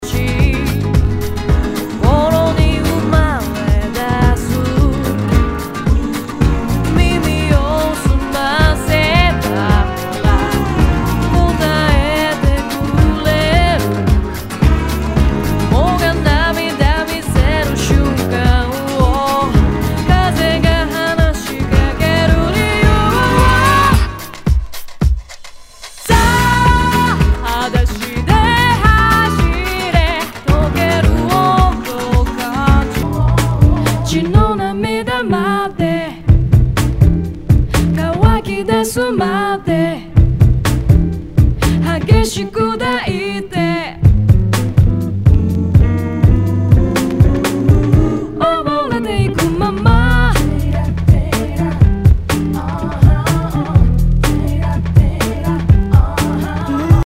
HOUSE/TECHNO/ELECTRO
ナイス！ヴォーカル・ハウス / ブレイクビーツ！